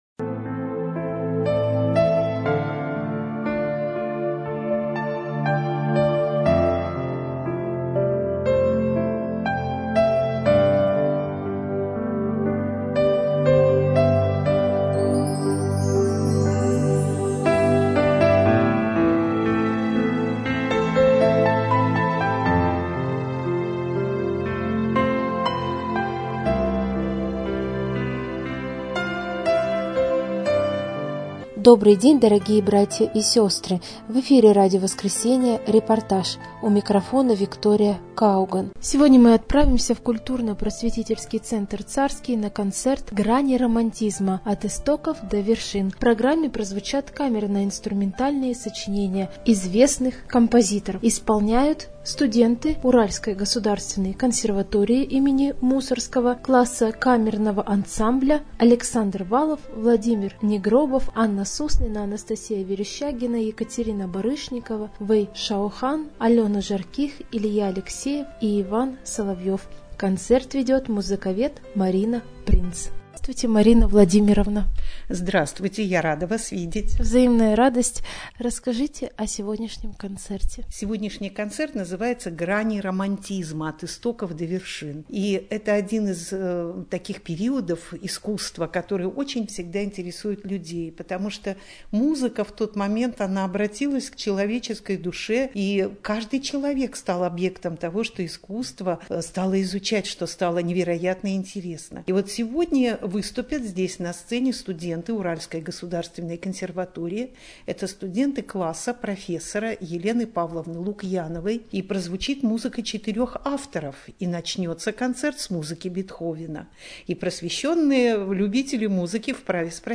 Концерт классической музыки в Центре Царский "Грани романтизма"
koncert_klassicheskoj_muzyki_v_centre_carskij_grani_romantizma.mp3